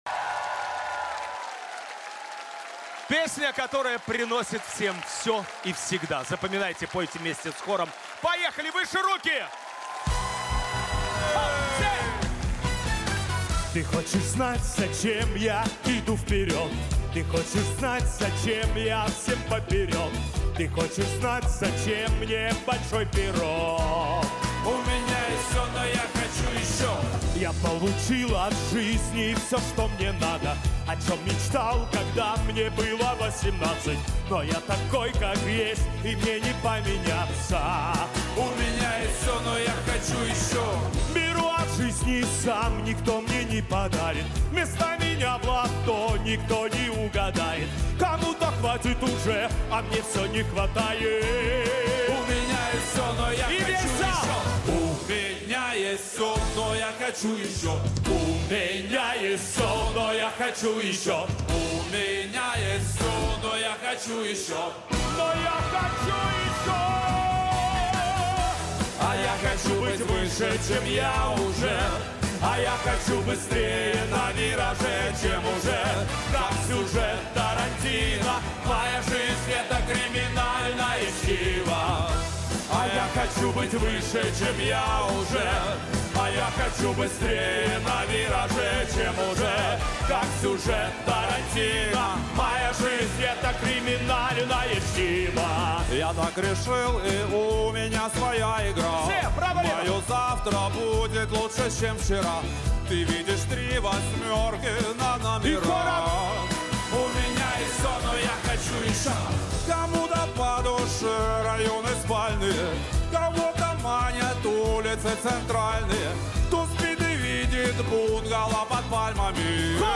Гала-концерт от 06.12.2024г.